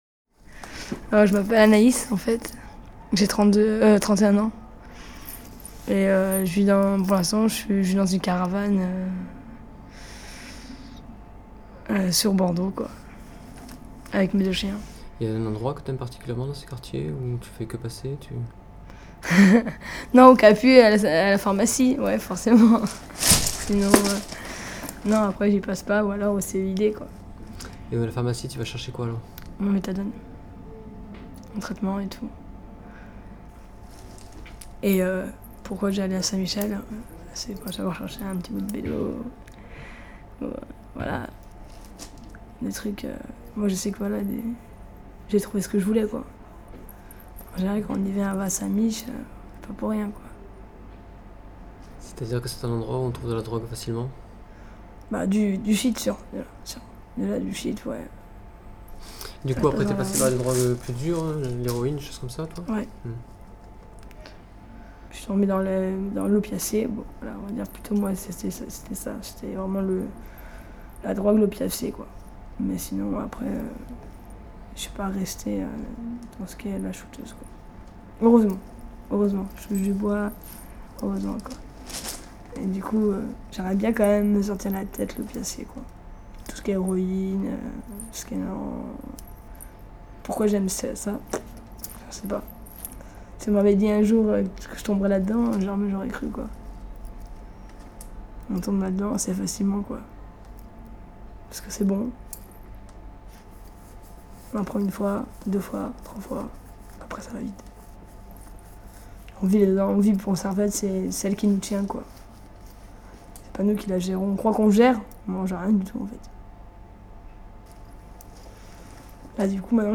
La pièce sonore De fil en aiguille propose une immersion dans la vie du CEID et donne la parole au personnel et aux personnes rencontrées.
La pièce sonore De fil en aiguille propose une immersion dans la vie du CEID et donne à entendre la parole du personnel qui aide, écoute et soigne, tout comme celle de ces personnes dont les parcours de vie sont fragilisés par des problèmes d’addiction.